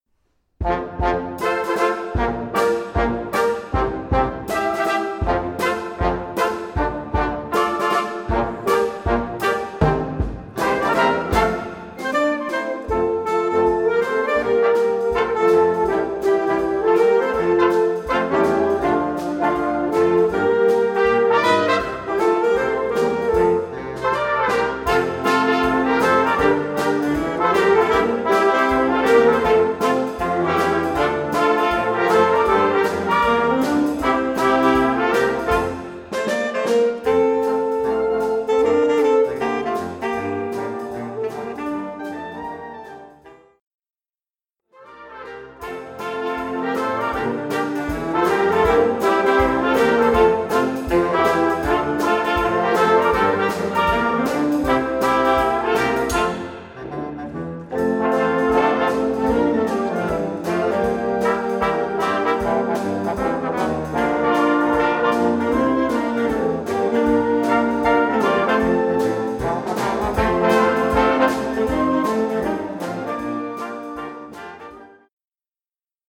Wind Band (harmonie)
Marches